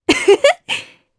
Naila-Vox_Happy2_jp.wav